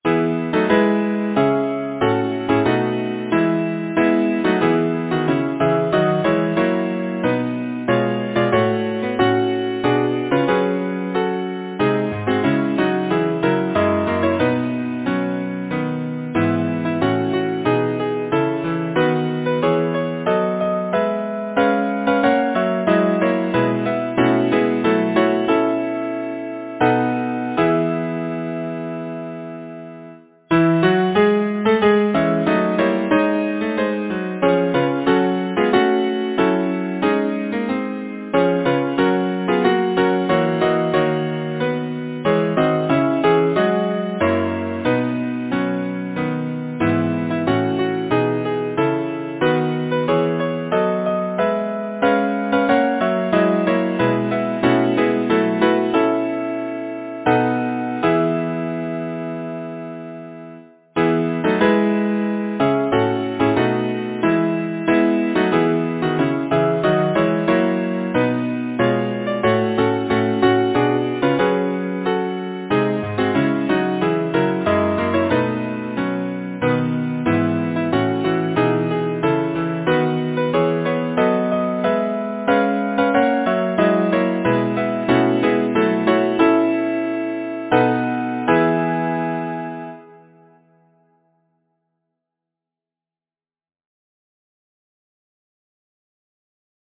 Title: Queen and huntress Composer: William Wooding Starmer Lyricist: Ben Jonson Number of voices: 4vv Voicing: SATB Genre: Secular, Partsong
Language: English Instruments: A cappella